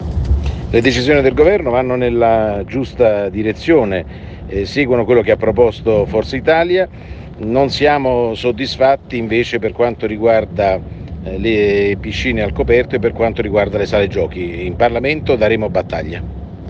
Ecco l’audio integrale dell’intervento al Gr Radio del coordinatore nazionale di Forza Italia, Antonio Tajani.